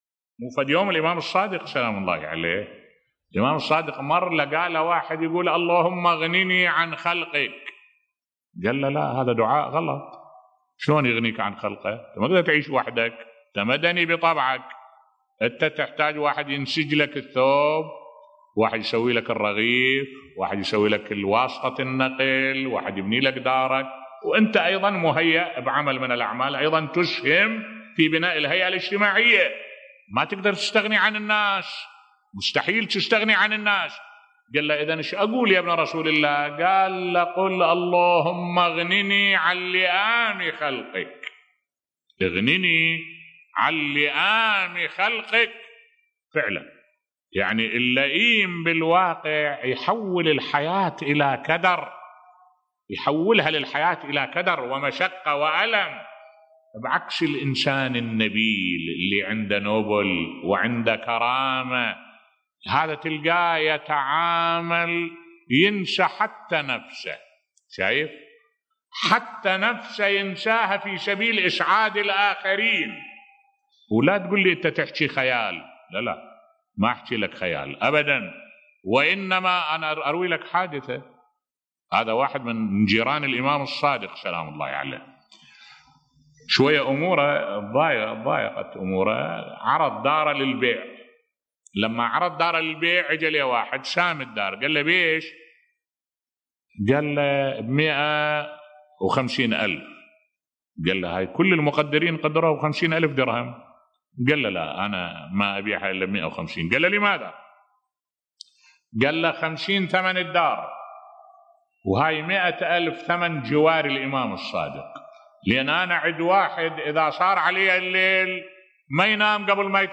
ملف صوتی الفرق بين اللئيم والنبيل بصوت الشيخ الدكتور أحمد الوائلي